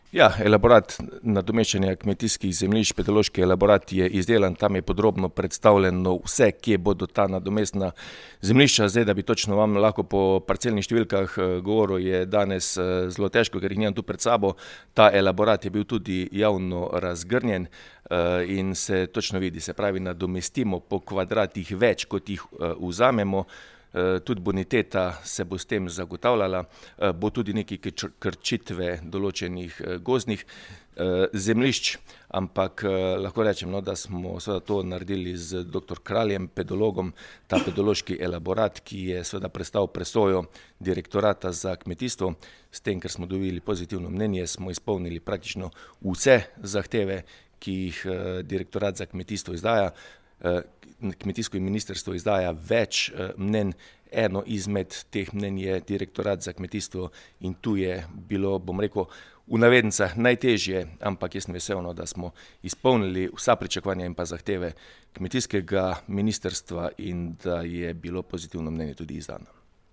Kje bodo nadomestna zemljišča z enako bonitetno oceno? (izjava župana)
Na vprašanje, kje bodo ta zemljišča z enako bonitetno oceno in enakimi pogoji pridelave, torej kje so ta nadomestna enakokakovostna kmetijska zemljišča, odgovarja župan Mestne občine Slovenj Gradec Tilen Klugler:
Izjava Klugler - kmetijska zemljisca.mp3